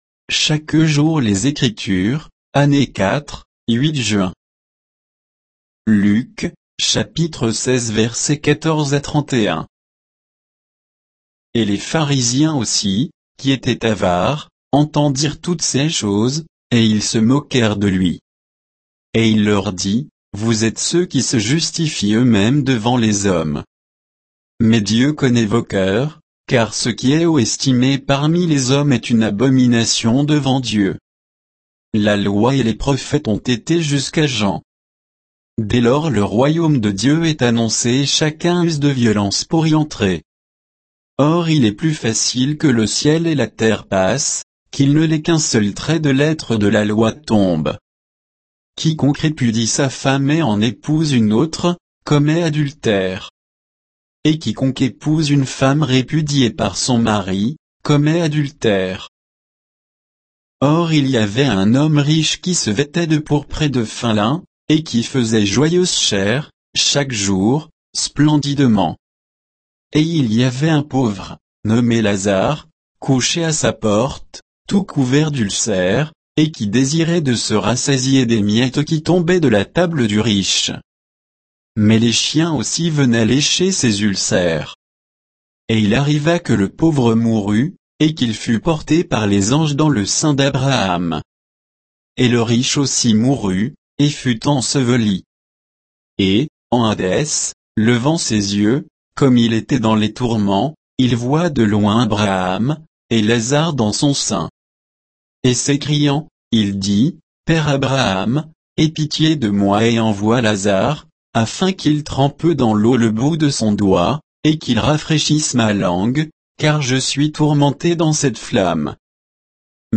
Méditation quoditienne de Chaque jour les Écritures sur Luc 16, 14 à 31